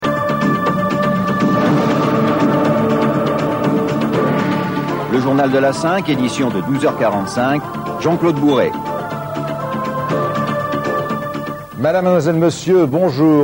Voix off : Le journal de La Cinq, Jean-Claude Bourret ( 1992 - 00:13 - MP3 )